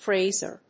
/ˈfrezɝ(米国英語), ˈfreɪzɜ:(英国英語)/